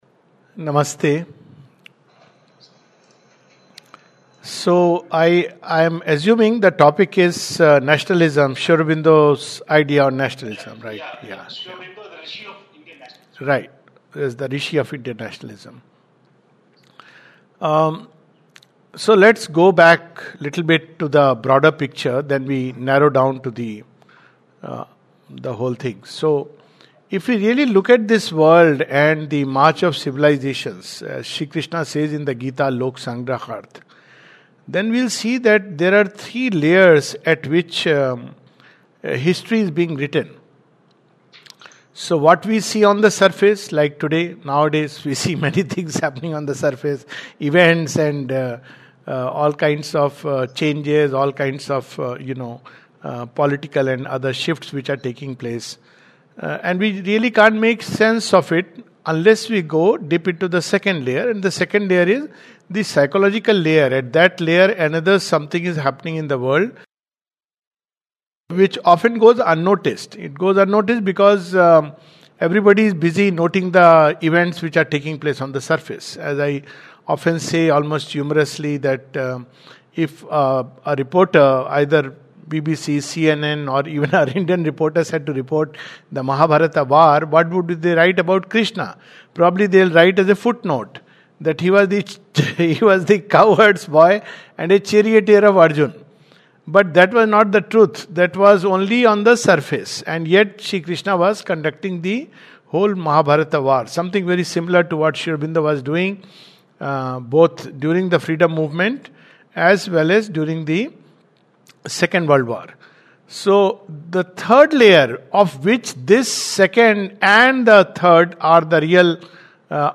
This is a webinar organised on September 25, 2025 at Pondicherry for the IKS certificate course. The follow-up questions were: 1) The Three Madnesses mentioned in Sri Aurobindo’s letter 2) Sanatana Dharma 3) India's Future 4) Hindu-Muslim Problem 5) New Forms in Sanatana Dharma